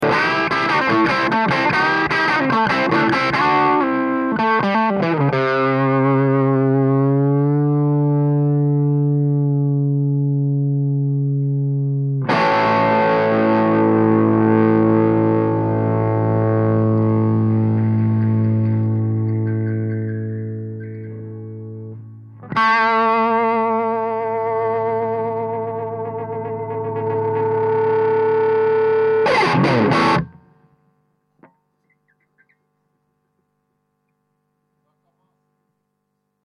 - Copie du son du canal "Blue" du Bogner XTC.